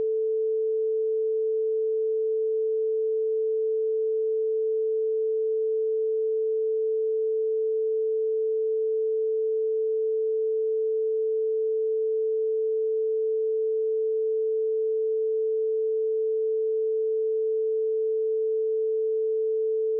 Tonalite.mp3